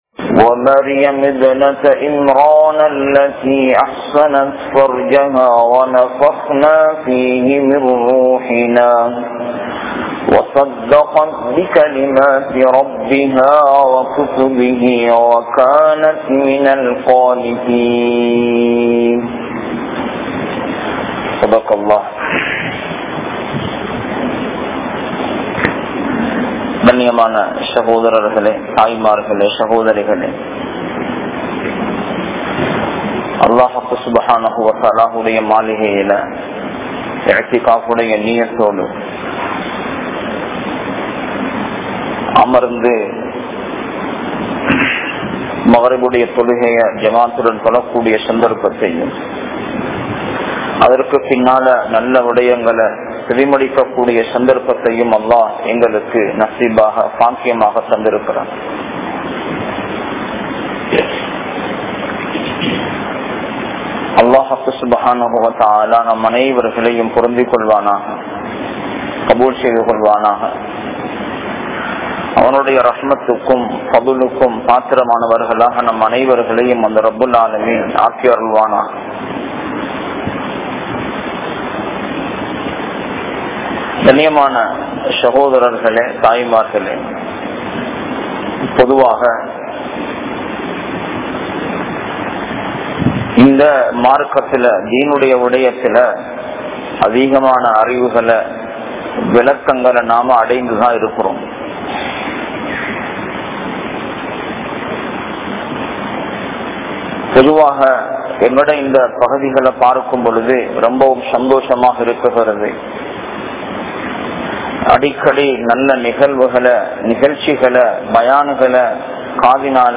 Eemaaniya Pengal (ஈமானிய பெண்கள்) | Audio Bayans | All Ceylon Muslim Youth Community | Addalaichenai